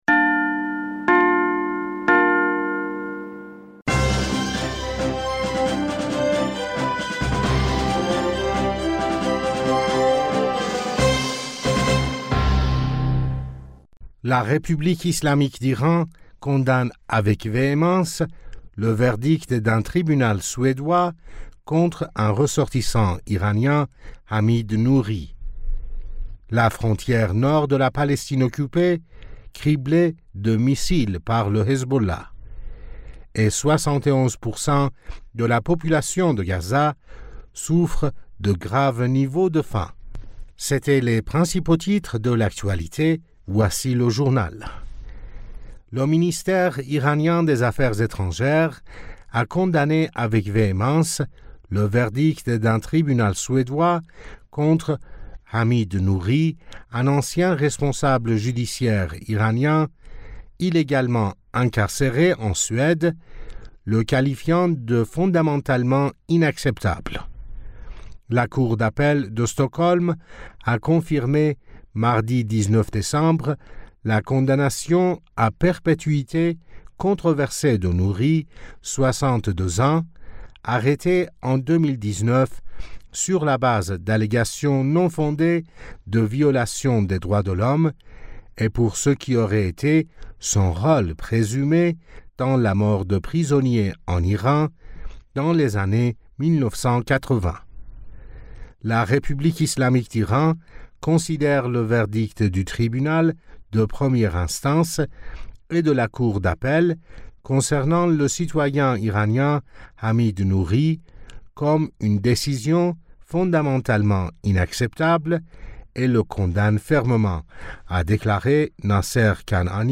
Bulletin d'information du 20 Decembre 2023